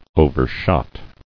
[o·ver·shot]